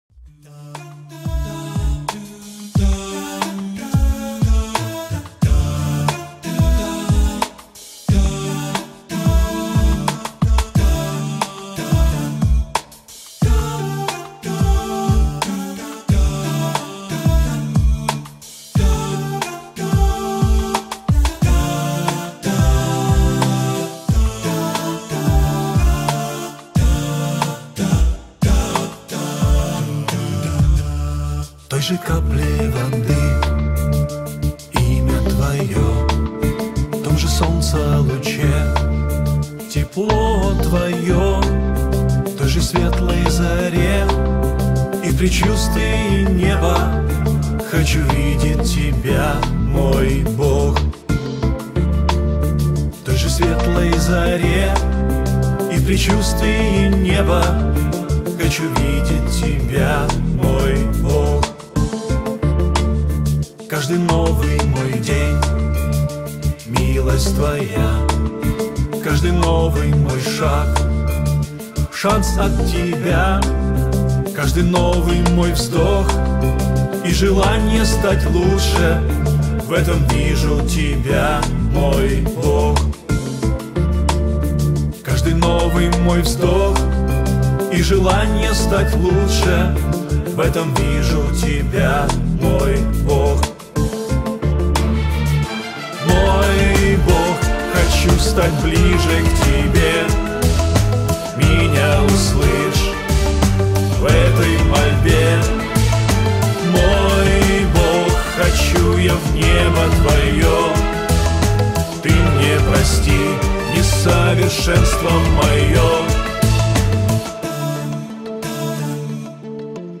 песня
89 просмотров 55 прослушиваний 4 скачивания BPM: 80